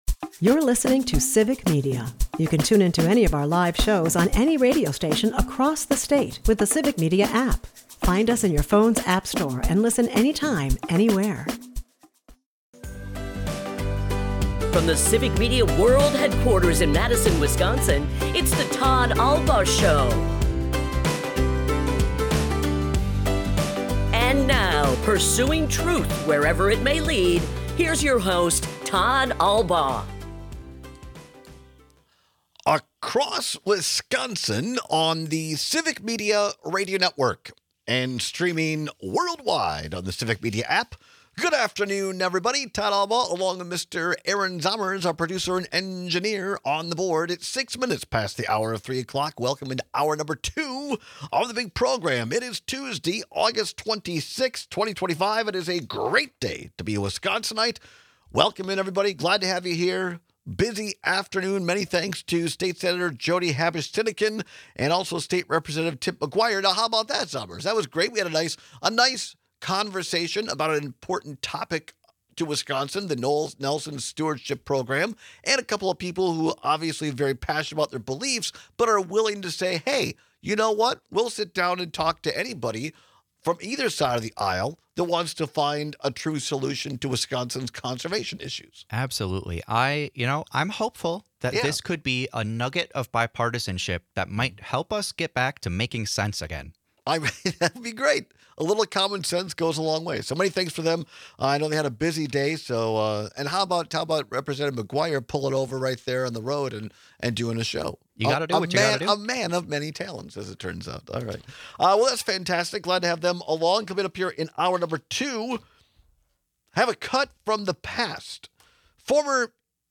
We take your calls and texts on whether you’d rather sleep on the floor or sink into a puddle.&nbsp